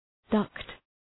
Shkrimi fonetik {dʌkt}